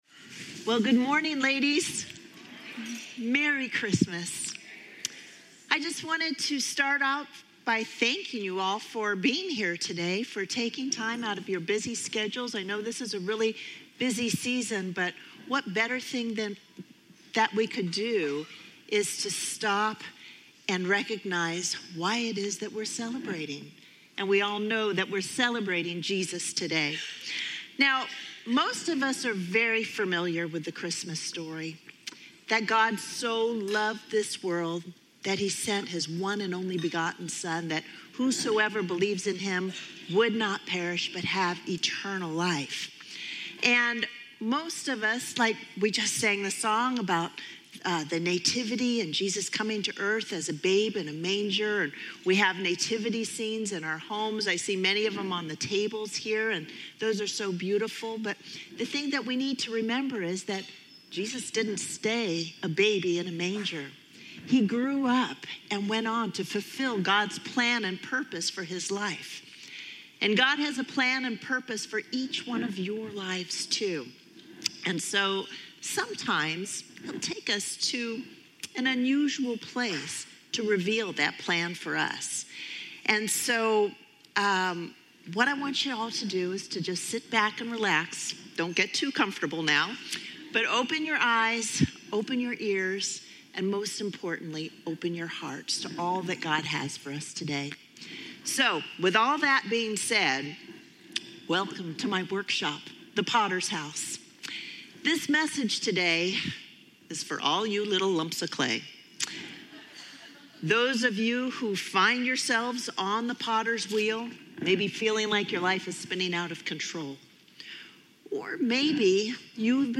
2024 Women's Christmas Tea